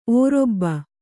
♪ ōrobba